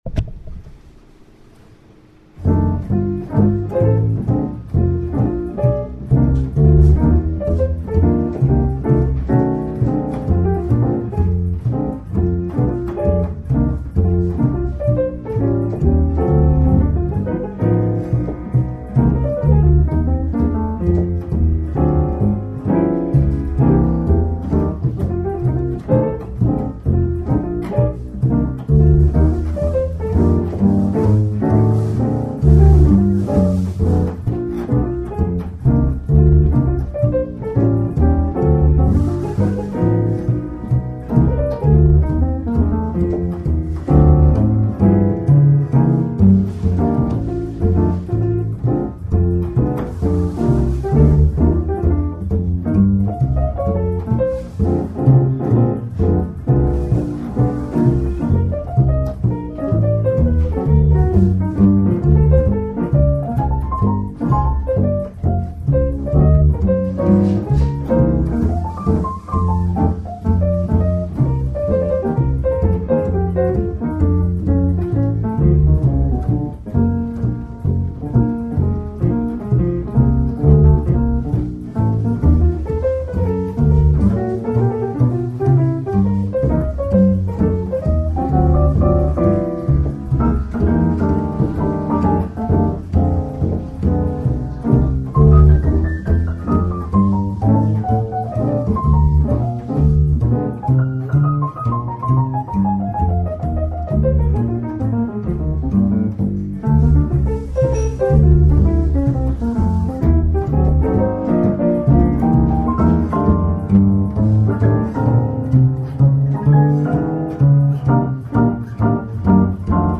恥ずかしながら．．ウッドベース練習の録音集です。
F-BLUES(Fブルース) 　ピアノ：自動演奏（MIDI）
ベース：筆者
所々音程も、リズムも怪しいですが、とりあえずTAKE1。